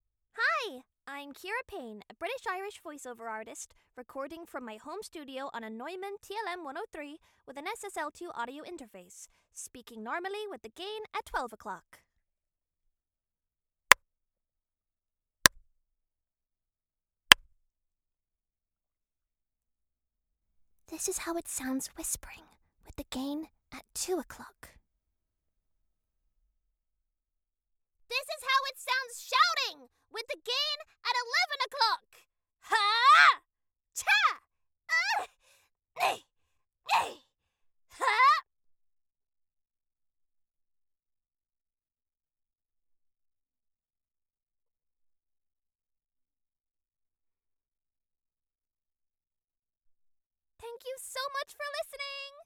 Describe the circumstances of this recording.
Home Studio Double-Walled (plywood-rockwool-plywood) self-built vocal booth, covered internally with vetted 50x50x7 European acoustic foam and 60x16x16 bass traps. Soundproof and Sound-treated. Raw Sample Neumann TLM102 SSL2+ interface